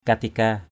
/ka-ti-ka:/ (d.) chuyên luận, sách giáo huấn = traité, code de formules, recueil de préceptes.